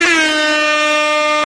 airhorn.mp3